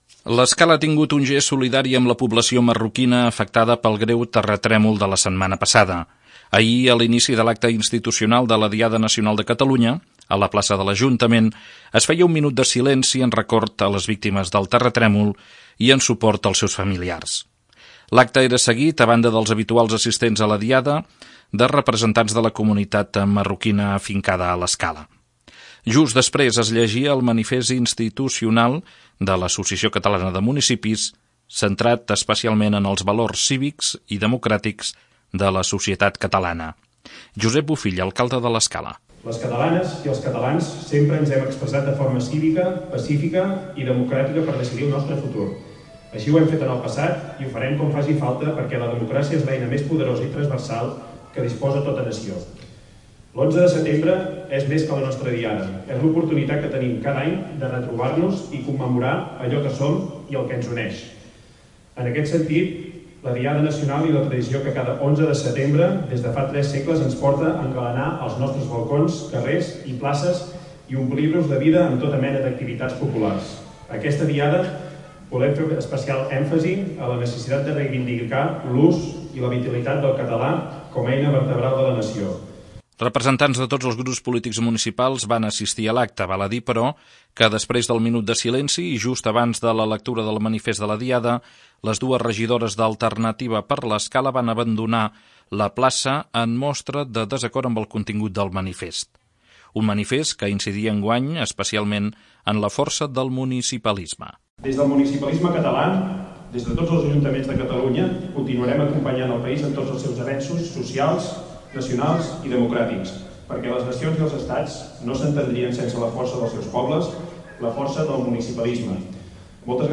Minut de silenci per les víctimes del terratrèmol del Marroc i manifest de l'AMC en l'acte institucional de la Diada, a la plaça de l'Ajuntament. L'acte, seguit per unes desenes d'espectadors, es va completar amb actuació del Cor Indika.
Per arrodonir l'acte, tal i com s'ha anat fent tradicional al llarg dels anys, el Cor Indika va interpretar un seguit de cants patriòtics.